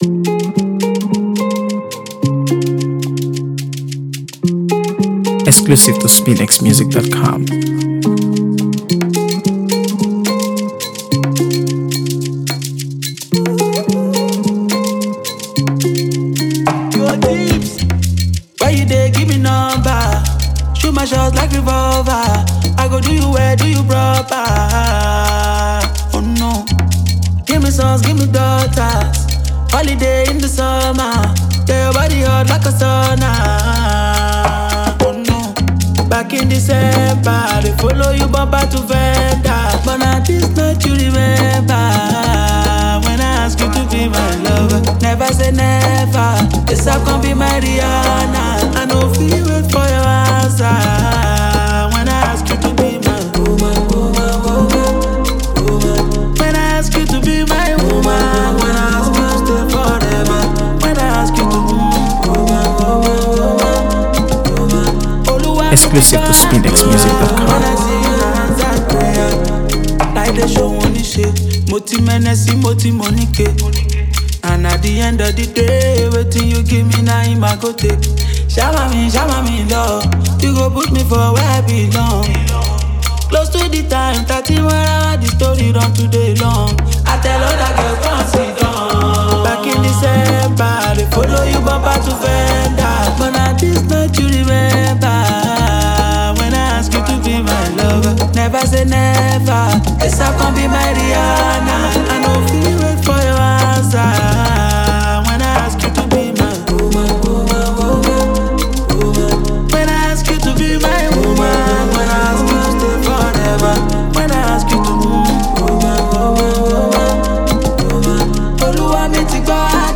AfroBeats | AfroBeats songs
blends catchy melodies with heartfelt lyrics